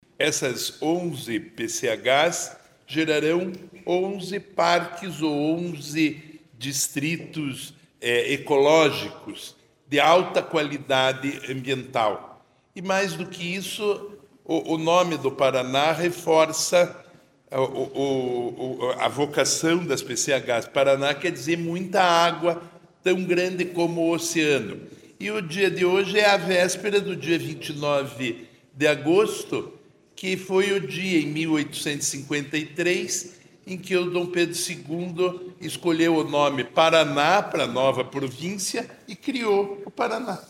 Sonora do secretário do Desenvolvimento Sustentável, Rafael Greca, sobre a construção de 11 novas PCHs no Paraná